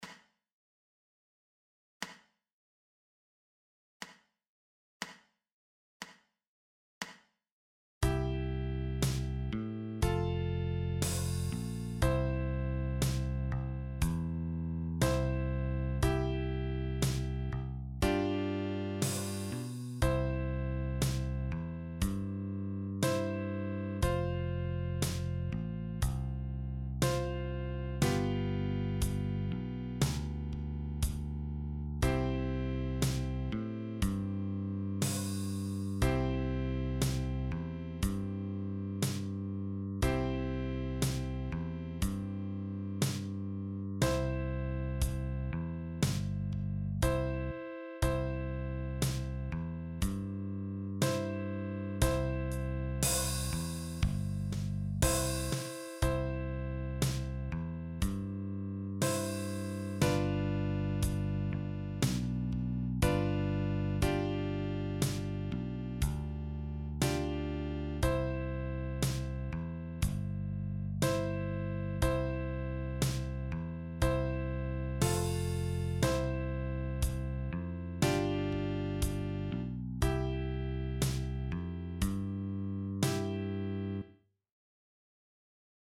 Sound samples – melody & band and band alone: